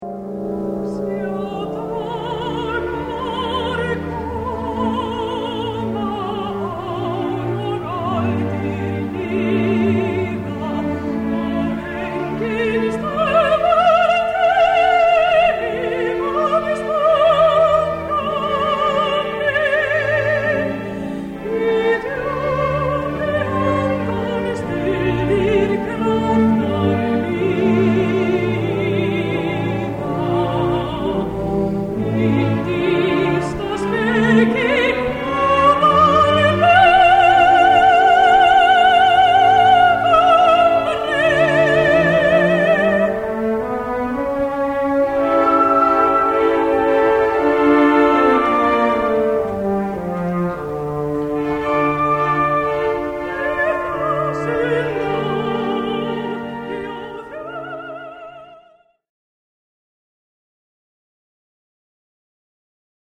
Flytjendur í tóndæminu: Söngsveitin Fílharmónía og Sinfóníuhljómsveit Íslands
undir stjórn Páls Pampikler Pálssonar.
Hljómsveitarútsetning Hallgrímur Helgason.
Sóló Sópranó.